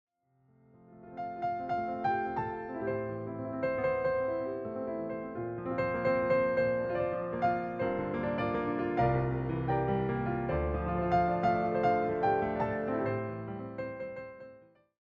all reimagined through solo piano.